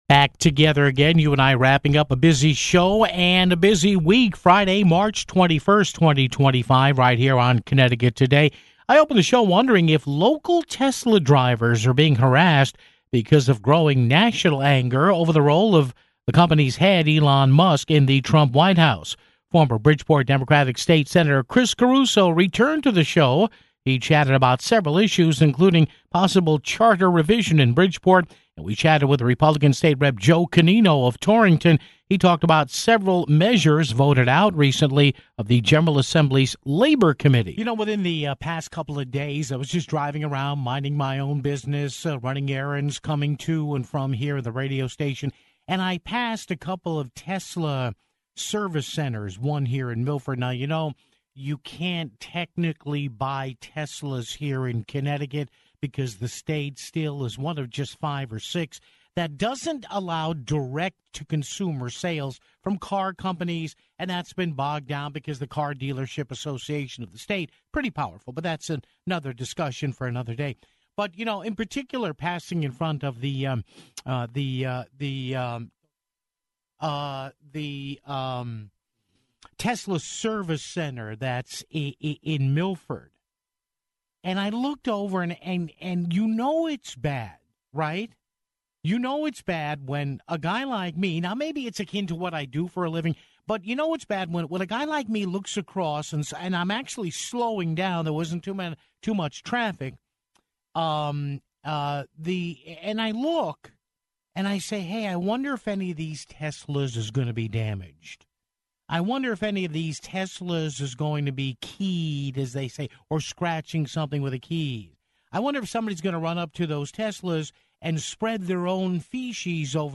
Former Bridgeport Democratic State Rep. Chris Caruso returned to the show to chat about several issues, including possible charter revision in Bridgeport (12:37). Finally, Torrington GOP State Rep. Joe Canino joined us to talked about several measures voted out of the General Assembly's Labor Committee (26:17)